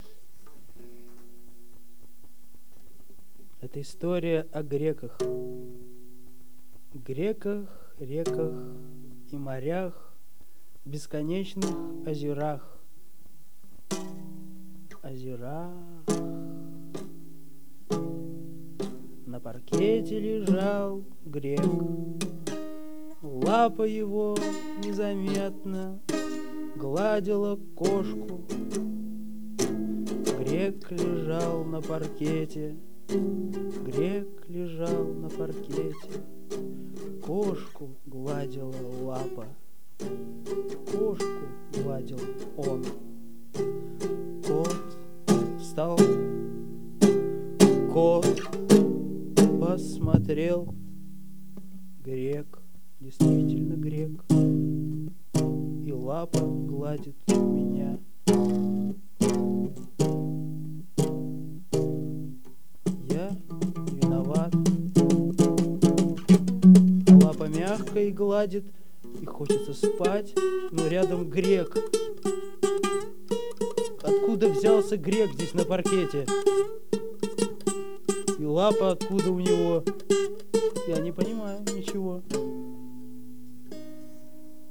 Мандолина и лежащий человек.